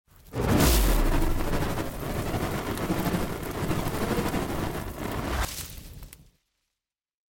دانلود آهنگ آتش 7 از افکت صوتی طبیعت و محیط
دانلود صدای آتش 7 از ساعد نیوز با لینک مستقیم و کیفیت بالا
جلوه های صوتی